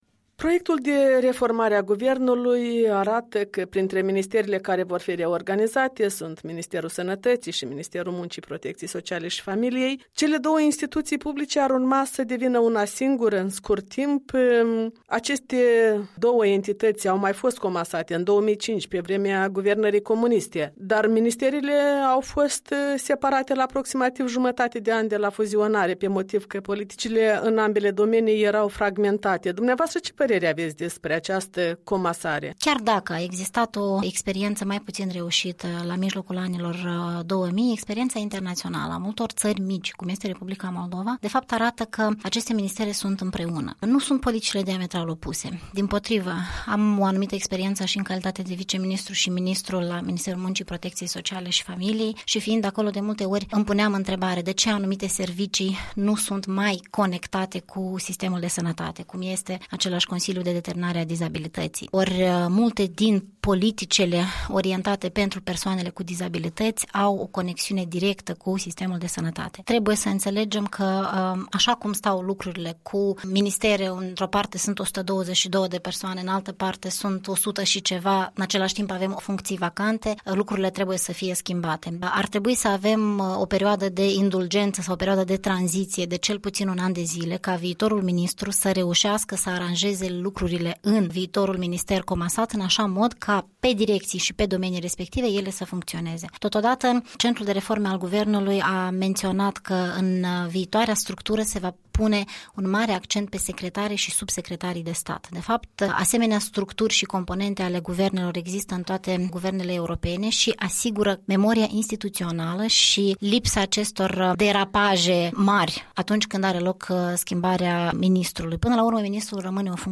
Ruxanda Glavan, ministrul Sănătății răspunde întrebărilor Europei Libere